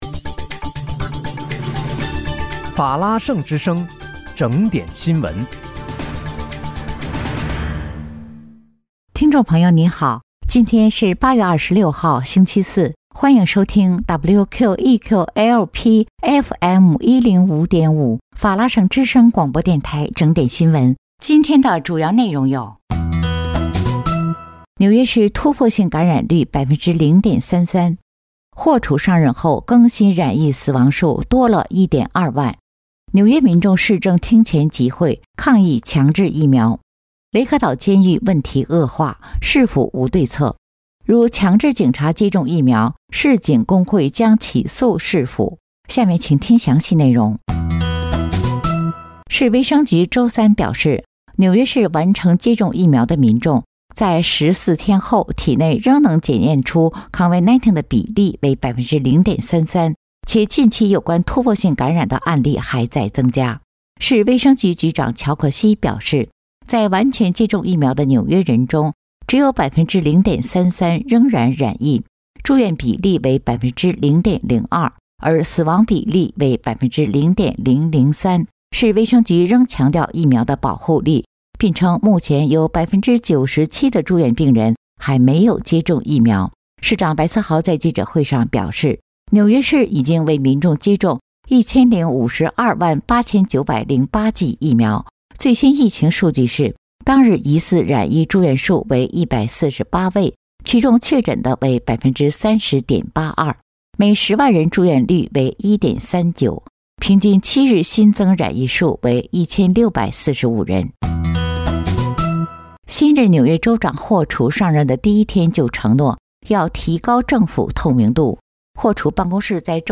8月26日（星期四）纽约整点新闻